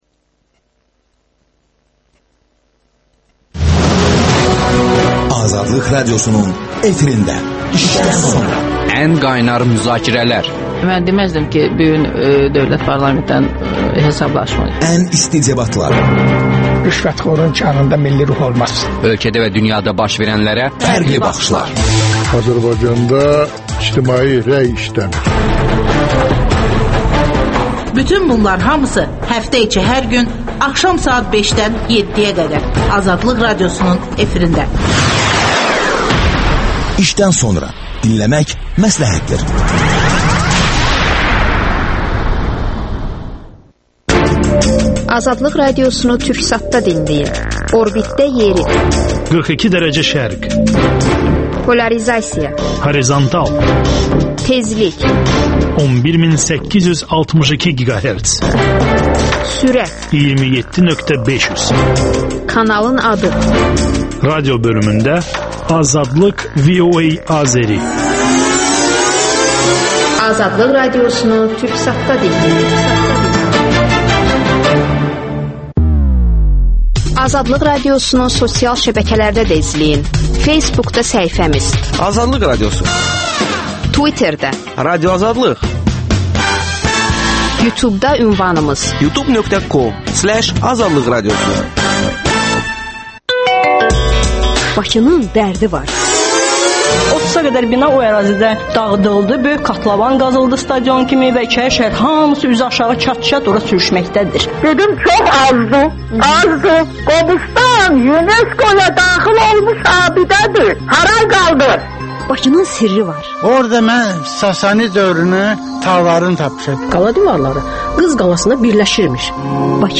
İşdən sonra - Teatral söhbət…